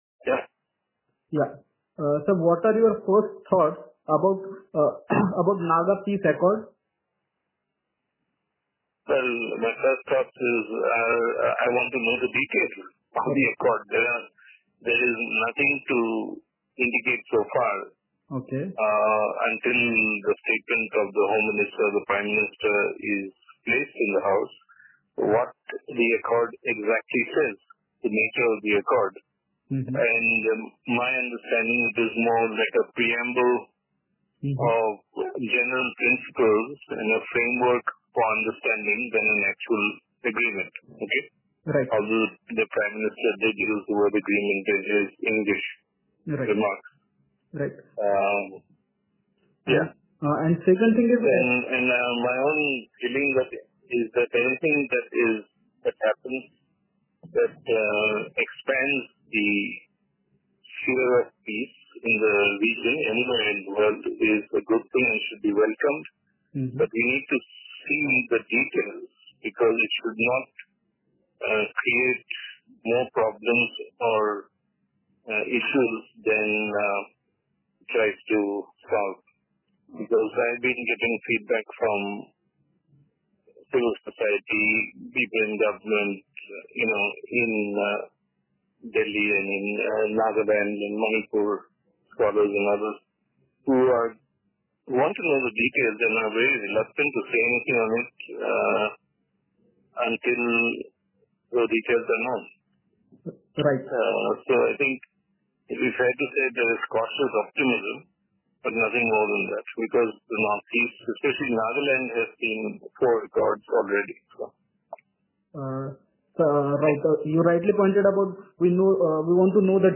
The central government and the National Socialist Council of Nagaland-Isak-Muivah on Monday (August 3, 2015) signed a historic accord. The South Asia Monitor spoke to noted expert Prof. Sanjoy Hazarika and asked for his first thoughts about the Naga peace accord.